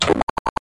neaktivnaia knopka Meme Sound Effect
Category: Games Soundboard